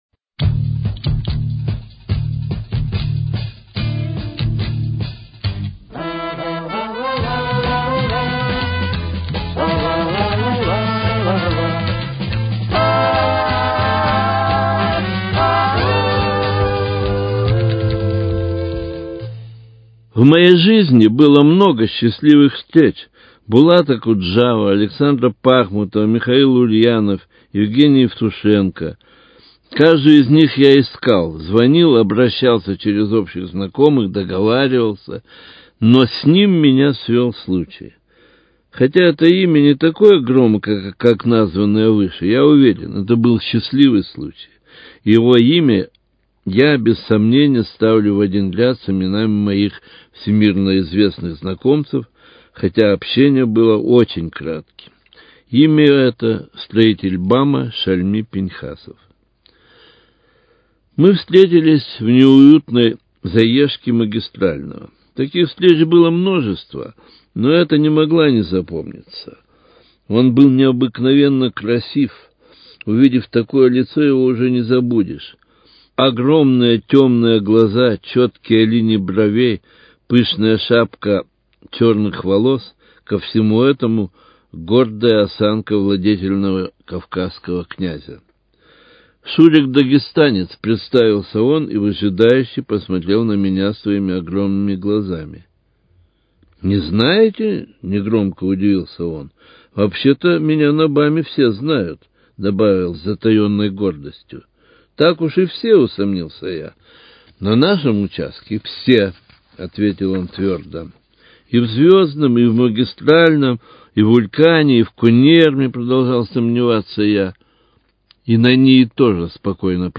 Цикл радио-очерков